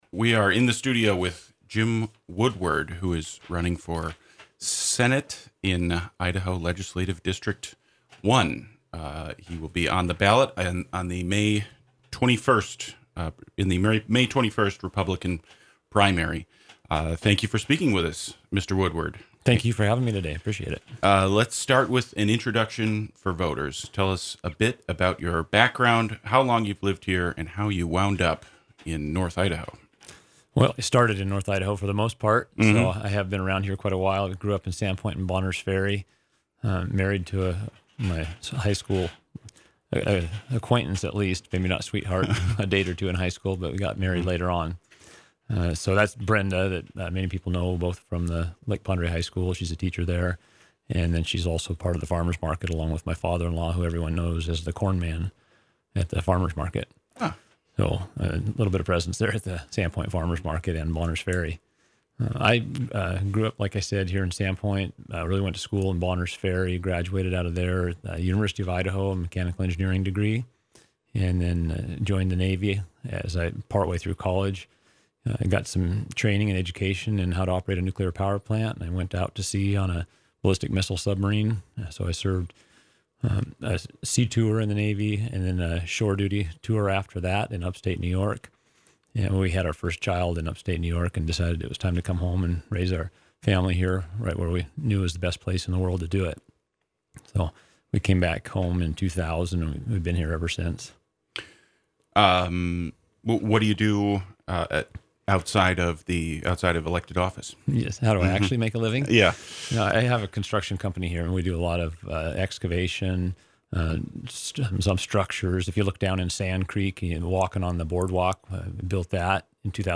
KRFY’s Local Decision series of interviews with candidates in contested races in the upcoming May 21 primary have aired weekly on the Morning Show.
Candidate interviews For KRFY’s Local Decision series, our Morning Show hosts conducted individual interviews with all candidates in contested races for the May 21 primary.